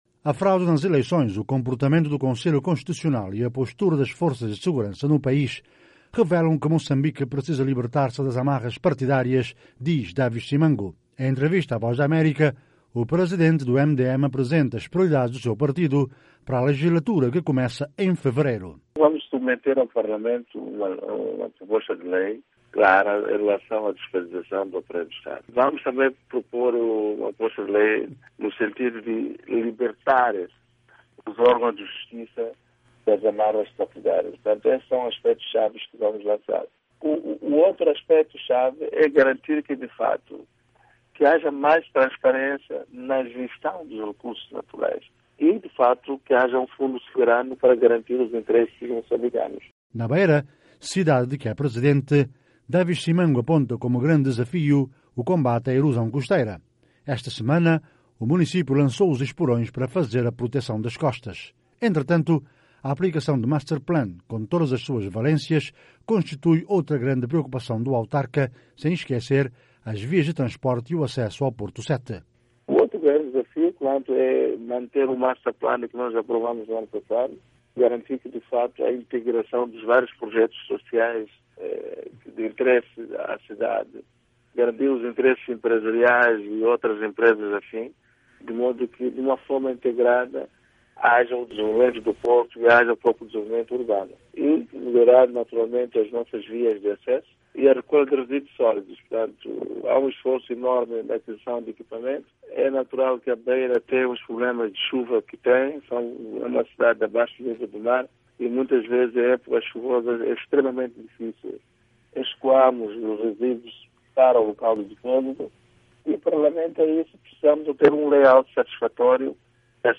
A garantia foi dada pelo presidente daquele partido, na oposição, que anuncia, em entrevista à VOA, a apresentação para breve de duas propostas de lei nesse sentido.